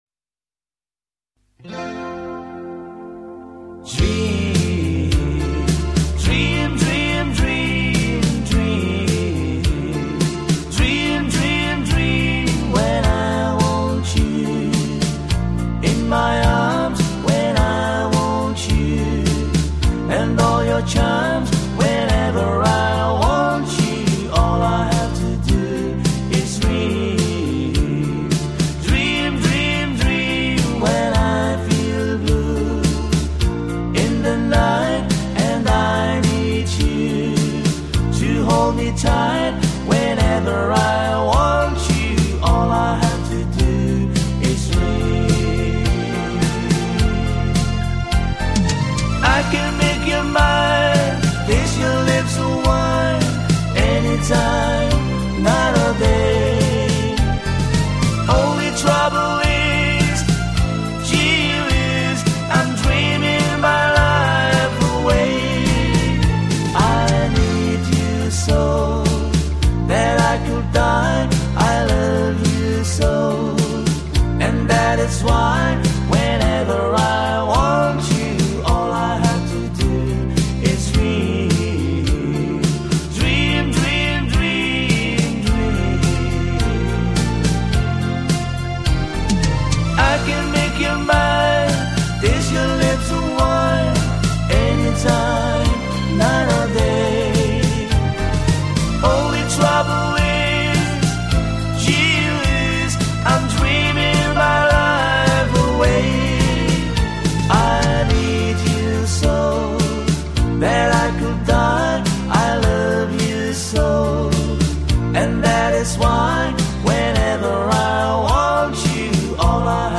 美国乡村音乐历史